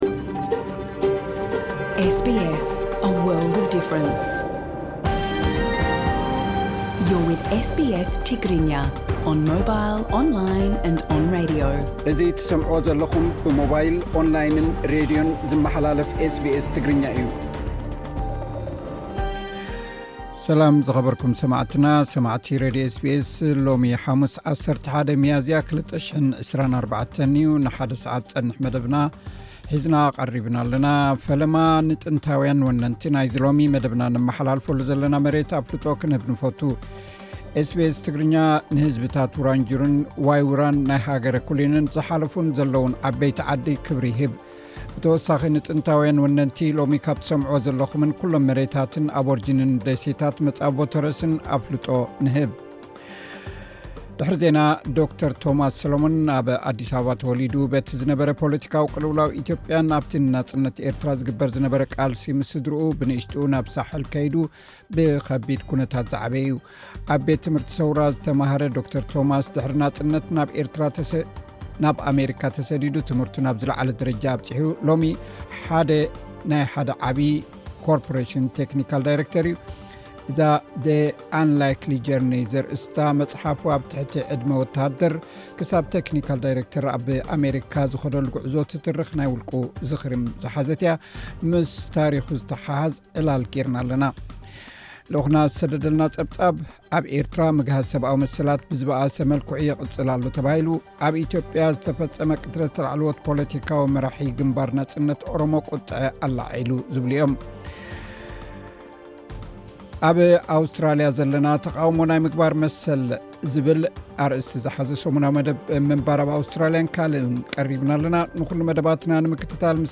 ዜና ኤስ ቢ ኤስ ትግርኛ (11 ሚያዝያ 2024)